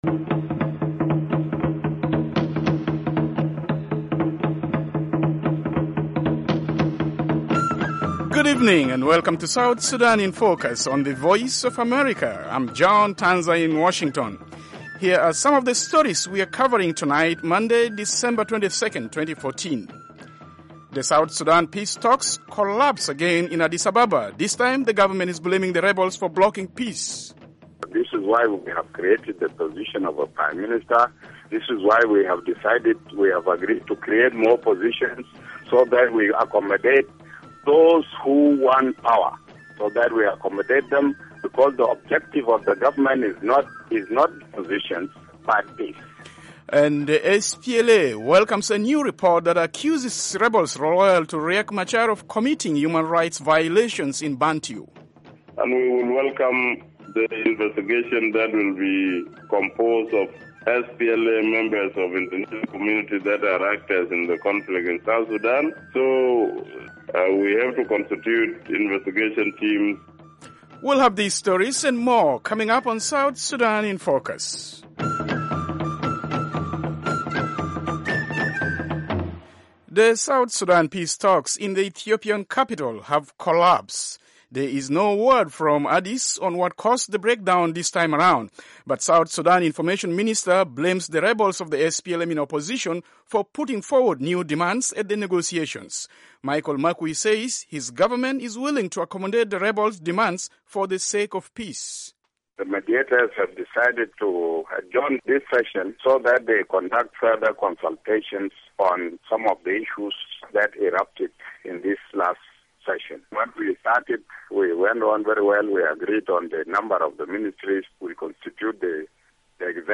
South Sudan in Focus is a 30-minute weekday English-language broadcast/internet program covering rapidly changing developments in the new nation of South Sudan and the region.